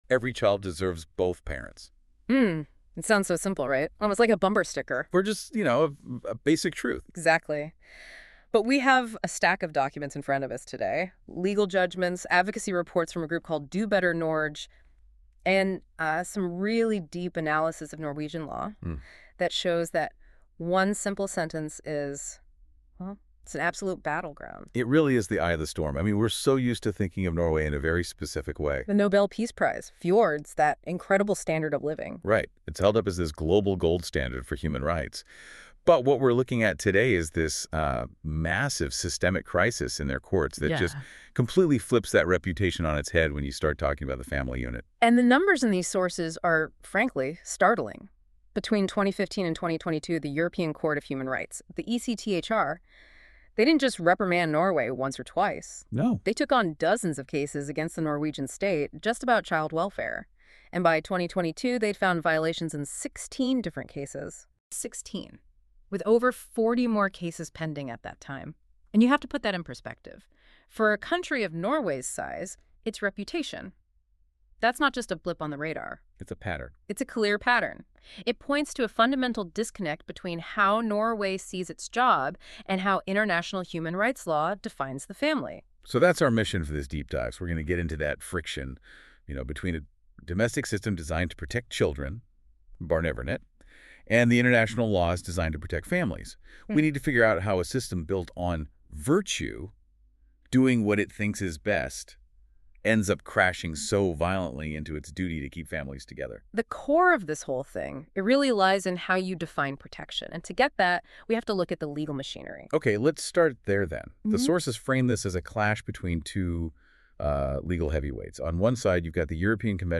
Listen to this comprehensive audio guide explaining Barnevernet, child welfare procedures, and parental rights in Norway.